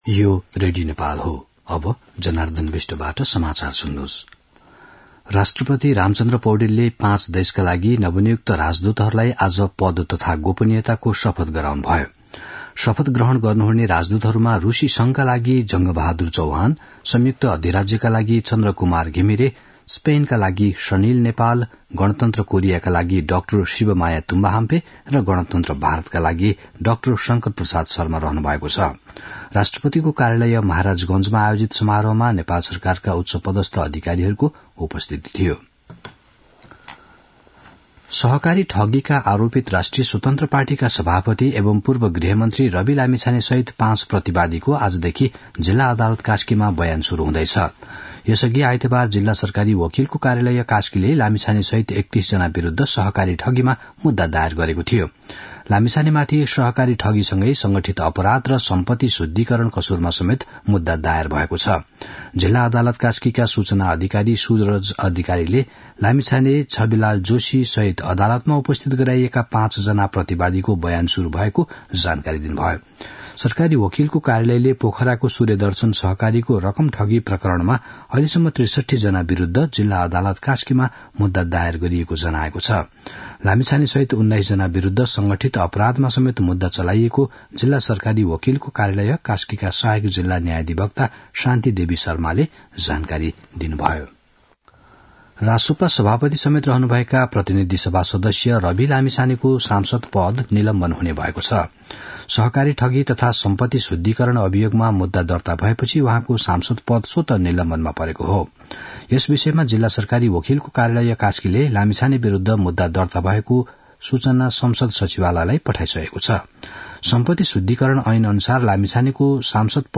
दिउँसो १ बजेको नेपाली समाचार : ९ पुष , २०८१
1-pm-nepali-news-1-17.mp3